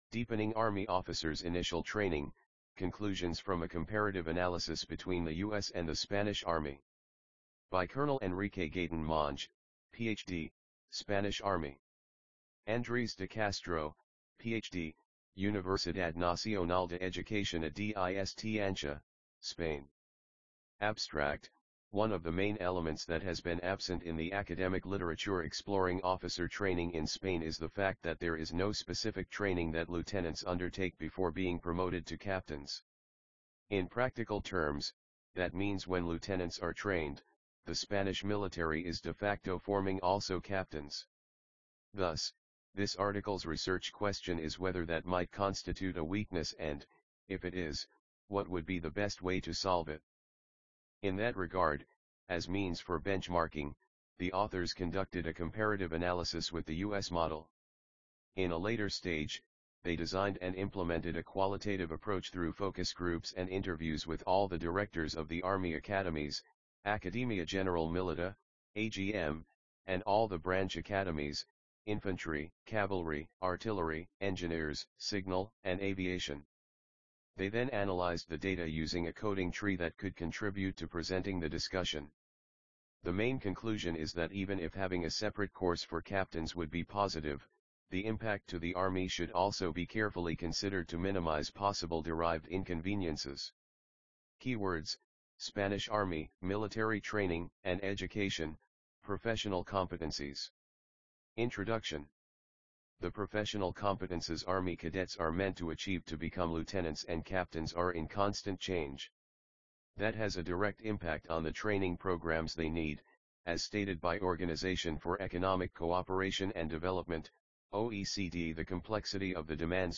IPME_2025_Deepening Army Officers Initial Training_MonjeDeCastro_AUDIOBOOK.mp3